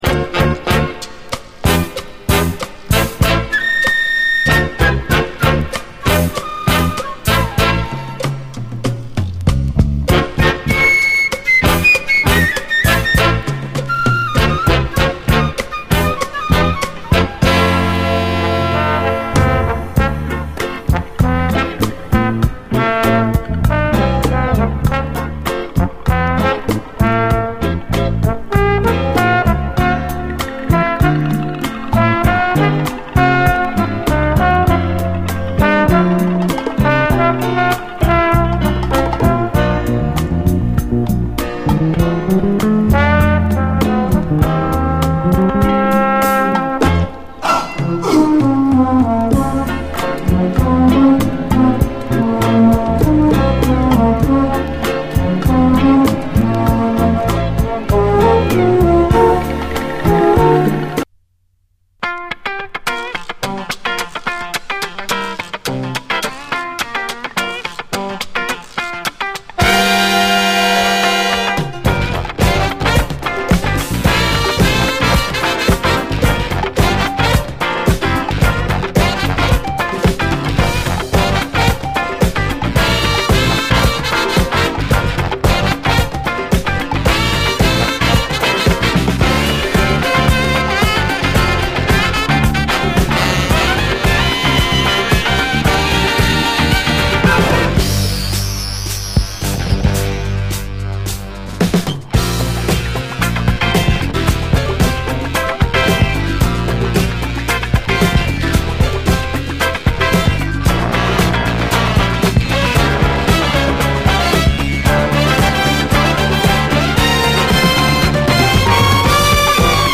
洒脱なムードのナイス・オールドタイミー・ディスコ！